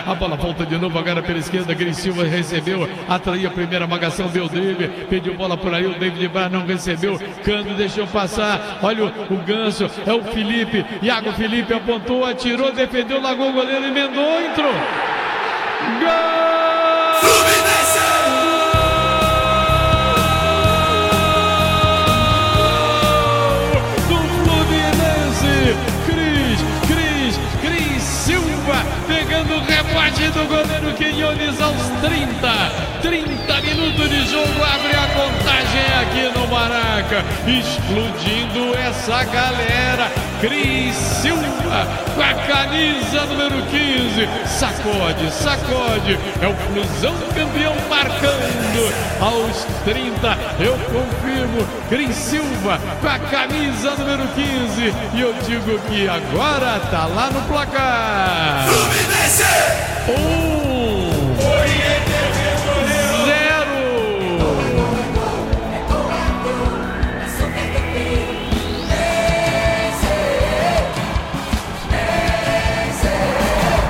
Ouça os gols da vitória do Fluminense sobre o Oriente Petrolero com a narração do Garotinho